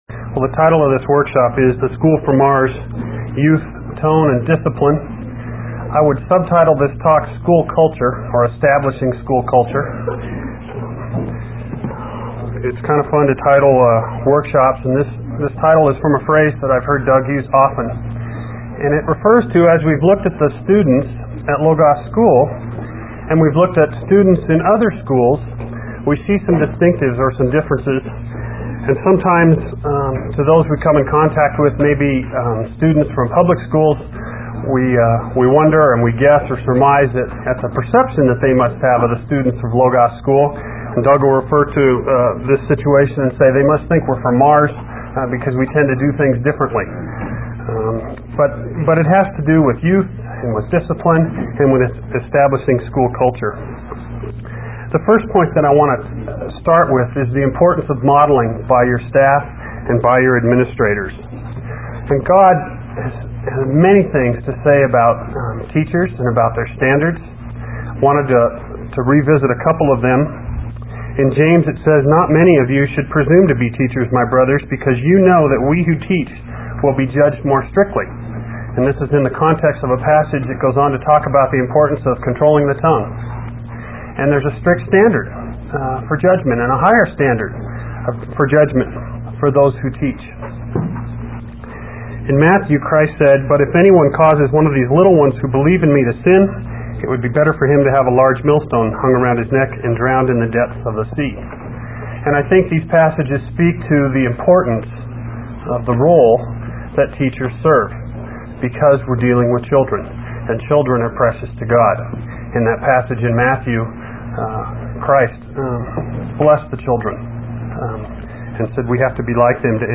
1995 Workshop Talk | 1:00:01 | All Grade Levels, Virtue, Character, Discipline